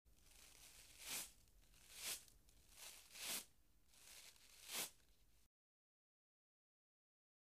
Combing | Sneak On The Lot
Rough Combing, Brushing Through Hair